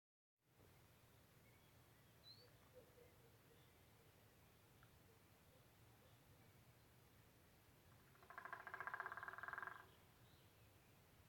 White-backed Woodpecker, Dendrocopos leucotos
StatusPermanent territory presumed through registration of territorial behaviour (song, etc.)
Notes/hiperaktīvs